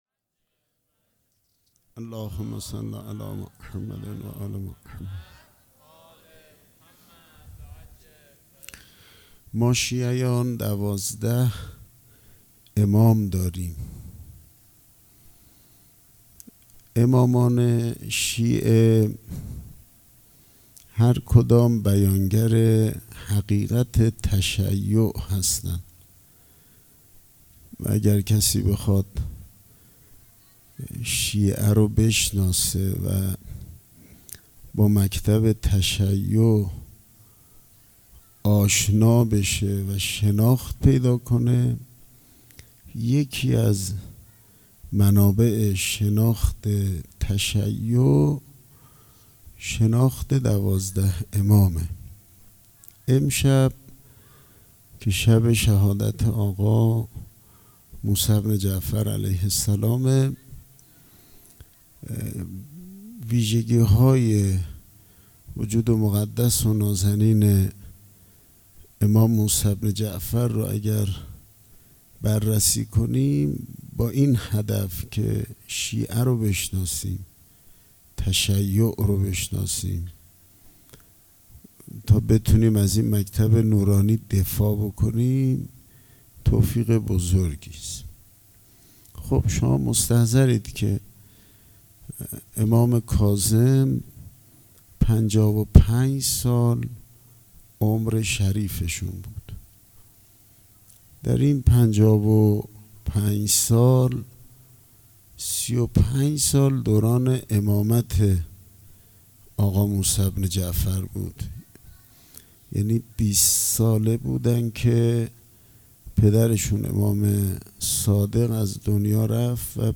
صوت مراسم شهادت امام موسی کاظم(ع)